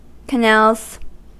Ääntäminen
Ääntäminen US : IPA : [kəˈnælz] Haettu sana löytyi näillä lähdekielillä: englanti Käännöksiä ei löytynyt valitulle kohdekielelle.